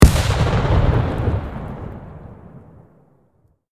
medium-explosion-6.ogg